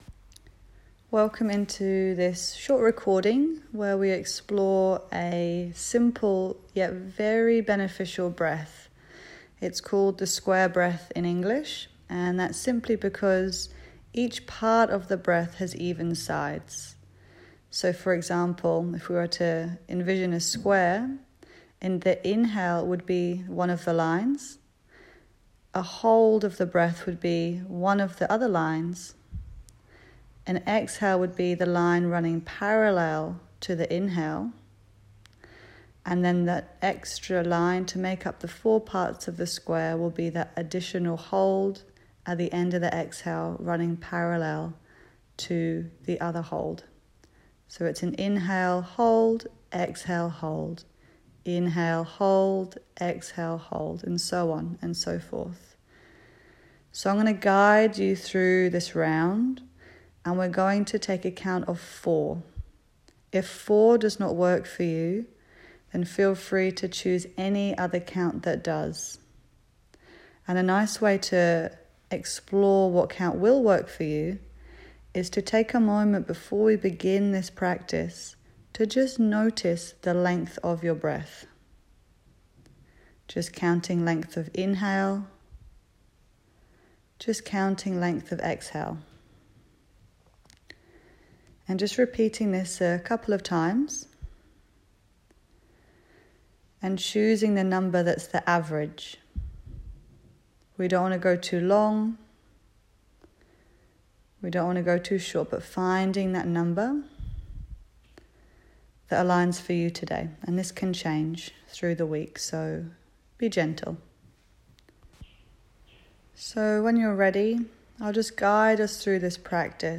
Square Breath – Guided Practice
Breath work yoga practice.